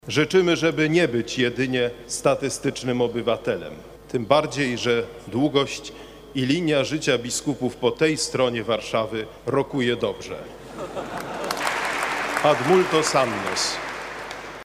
Z tej okazji w katedrze świętego Michała Archanioła i świętego Floriana Męczennika odprawiona została uroczysta msza święta z udziałem biskupów metropolii warszawskiej, na czele z kardynałem Kazimierzem Nyczem i abp. Tadeuszem Wojdą.